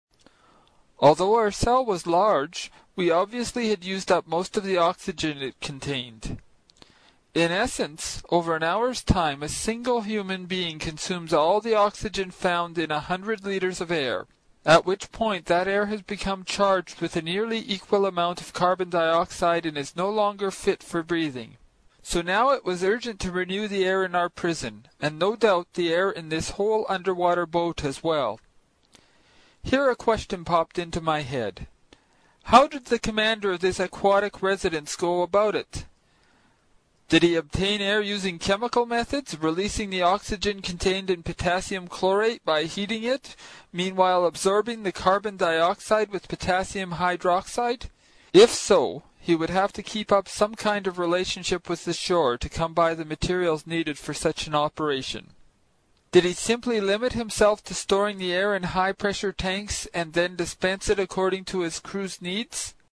在线英语听力室英语听书《海底两万里》第117期 第9章 尼德·兰的愤怒(2)的听力文件下载,《海底两万里》中英双语有声读物附MP3下载